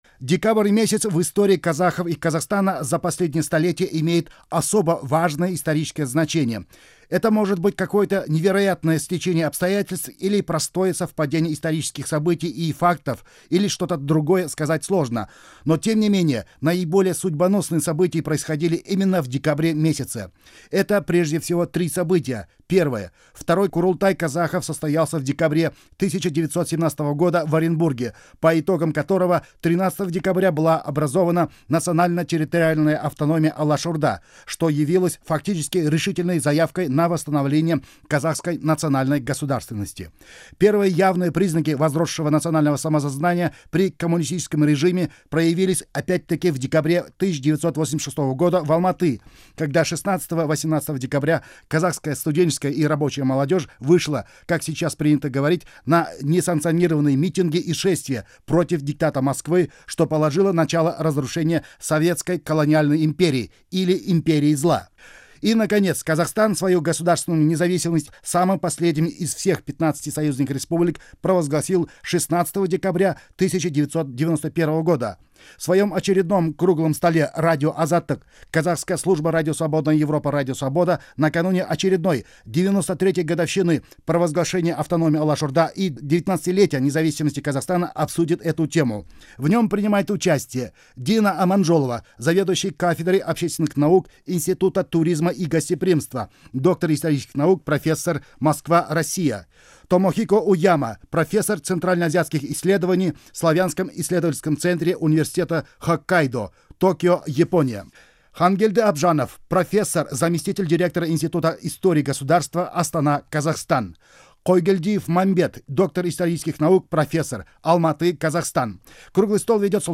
Аудиозапись Круглого стола